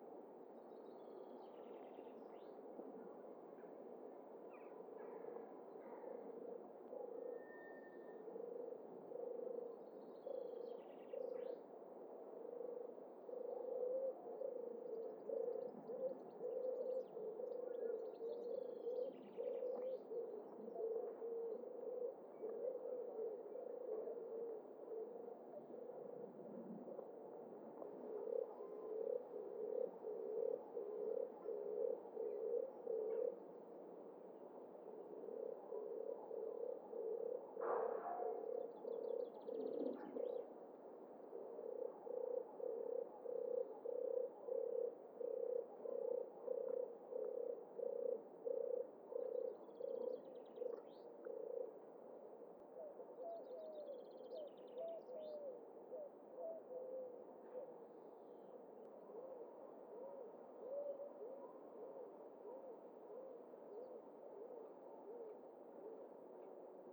Streptopelia turtur
Canto
O seu canto é un suave arrulo repetitivo, que se pode escoitar nos tranquilos campos e sebes durante a primavera e o verán.